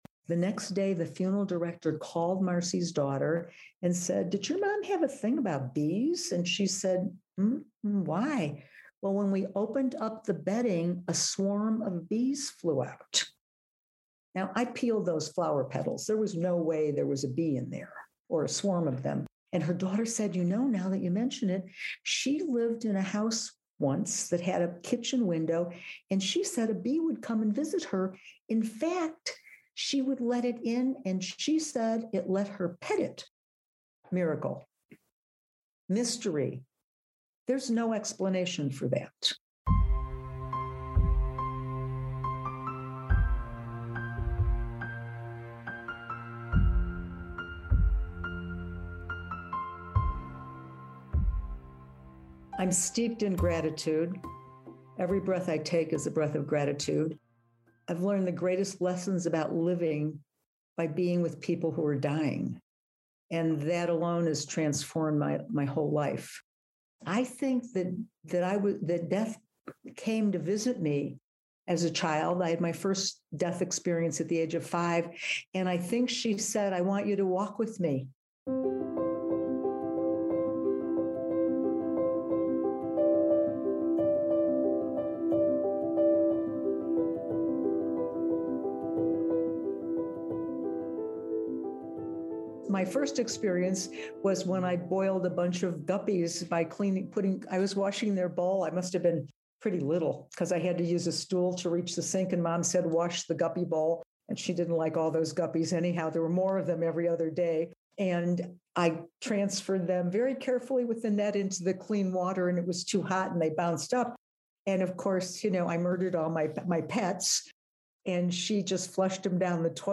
We had a seriously groovy conversation and I for one am grateful that I get to share my time here on this planet with her!